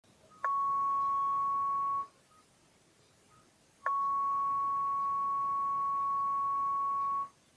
Записывал сотиком, около пьезика.
Вот и тональный прием на детекторный приемник, то чего так не хватало искровым радиостанциям начала века